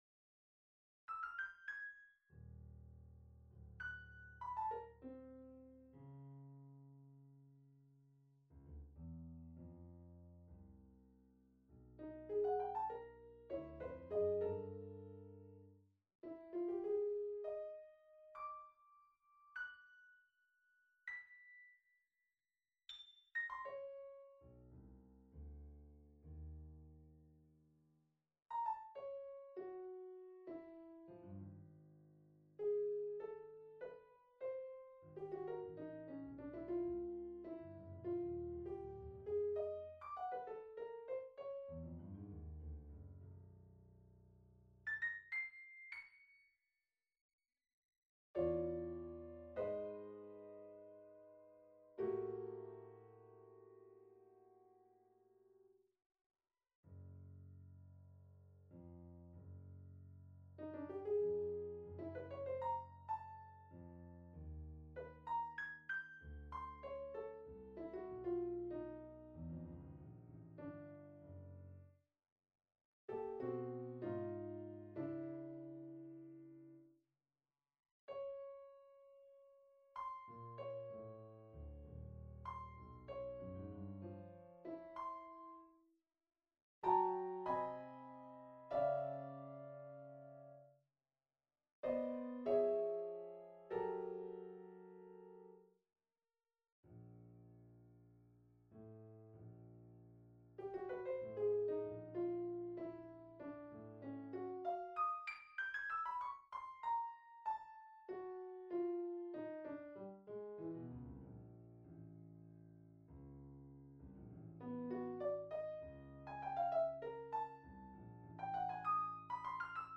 Sonata for Piano No.3 on a purpose-selected tone row Op.47 1. Andante risoluto 2. Adagio molto 3. Scherzo - Trio - Tempo I 4. Presto molto Date Duration Download 17 October 2013 27'14" Realization (.MP3) Score (.PDF) 37.4 MB 360 KB